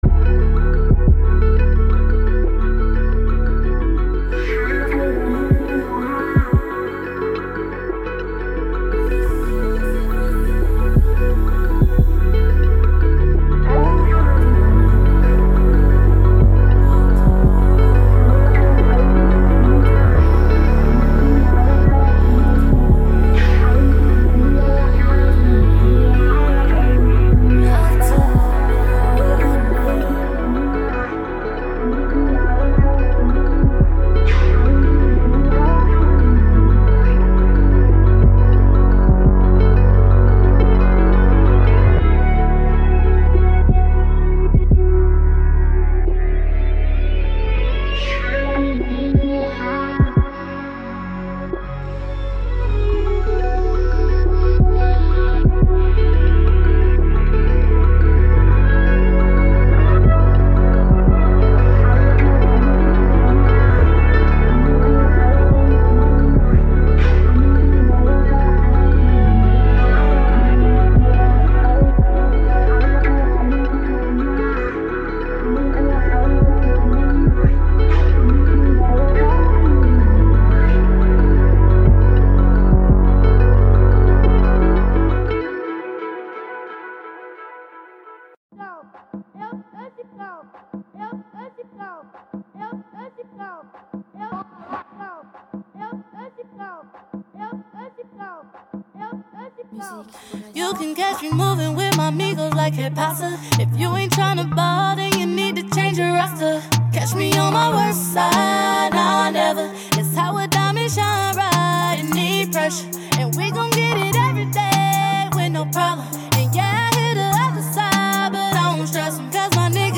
Hiphop
hot summer song